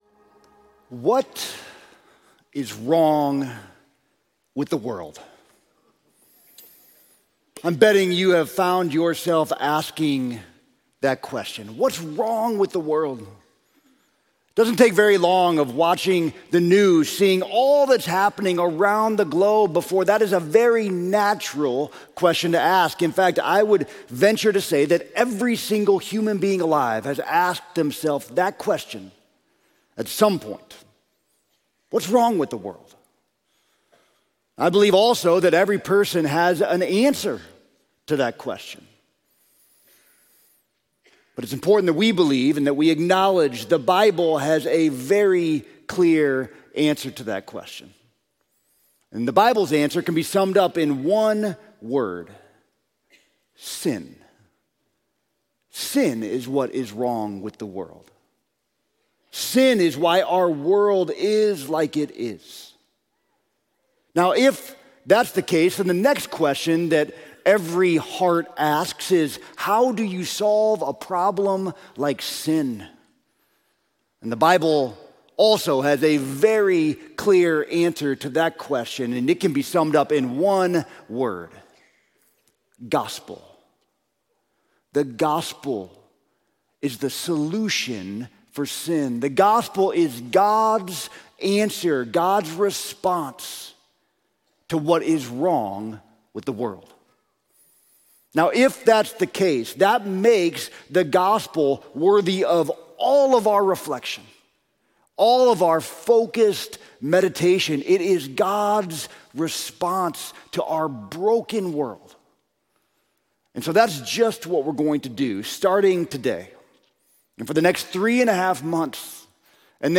We hope you find these sermons and service resources helpful to your journey.